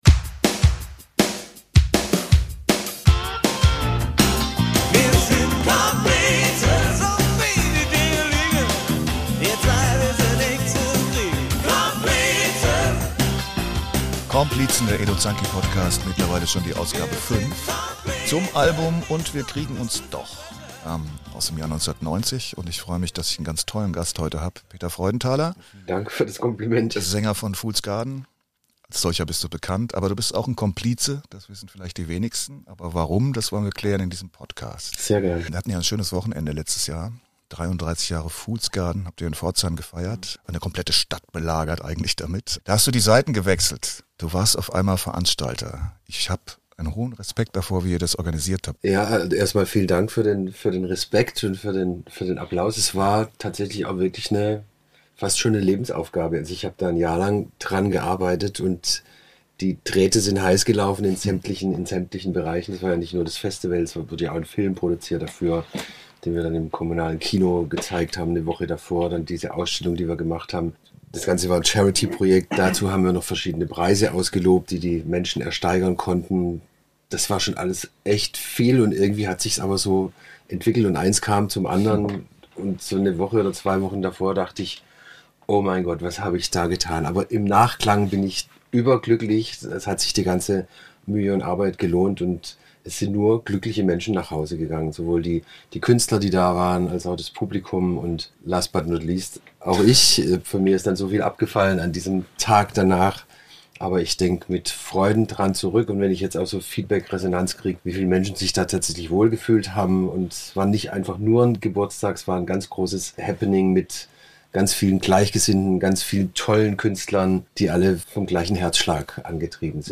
Live aus dem Kangaroo Studio in Karlsdorf-Neuthard.